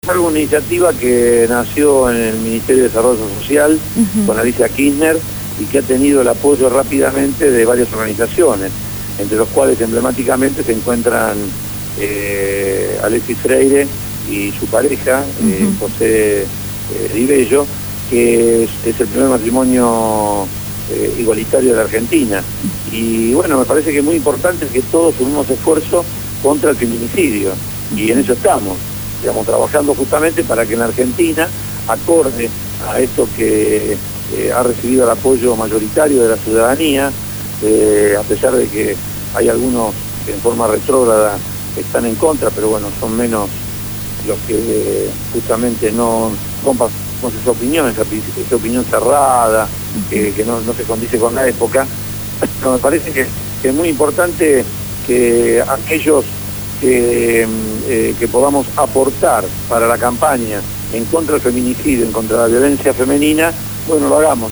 Fueron los dichos de Abel Fatala, Subsecretario de Obras Públicas de la Nación que fue entrevistado en el programa «Abramos la boca» (Lunes a viernes 16 a 18hs.) por Radio Gráfica.